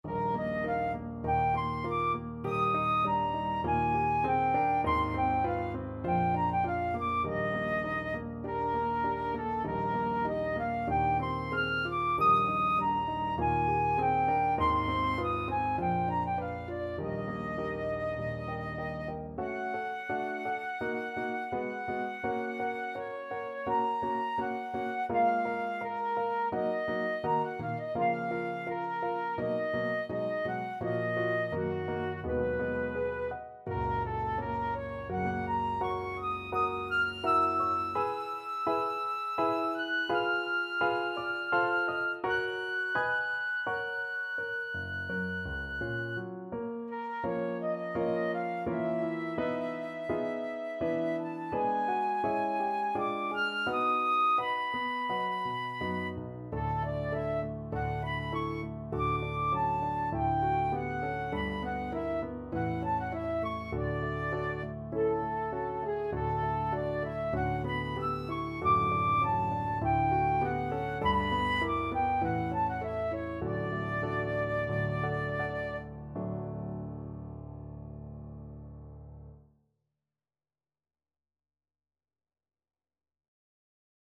4/4 (View more 4/4 Music)
Moderato
F#5-F#7
Classical (View more Classical Flute Music)